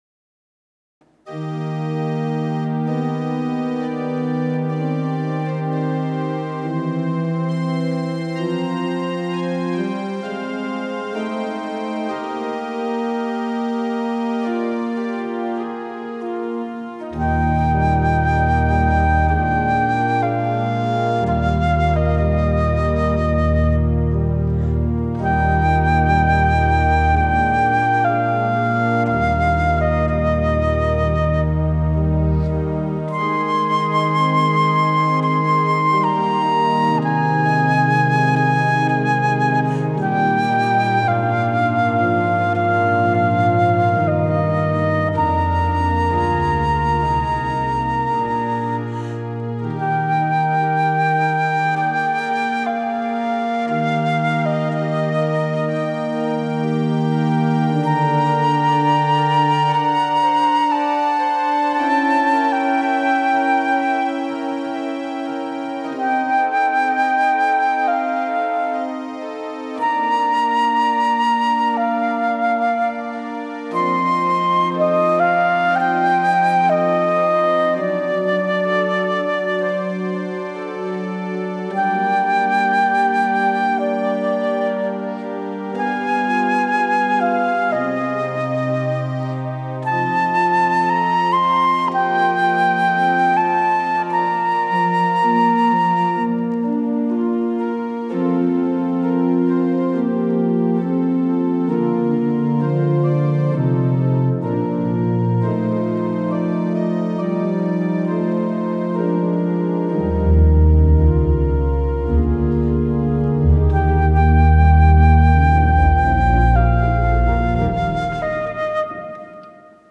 Sacred flute music for prayer, contemplation, worship
INSTRUMENTATION: Flute and piano or organ